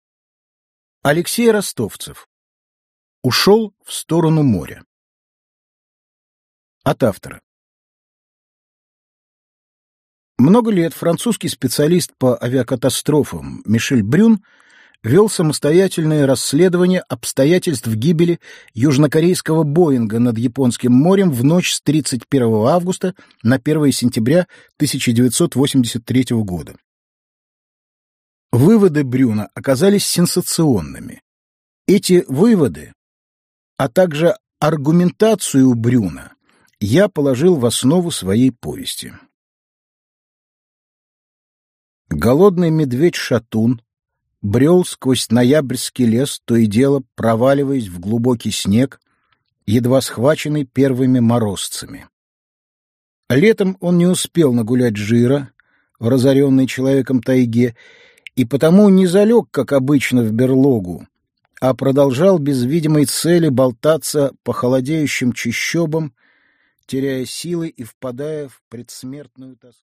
Аудиокнига Ушёл в сторону моря. Тайна проекта WH | Библиотека аудиокниг
Прослушать и бесплатно скачать фрагмент аудиокниги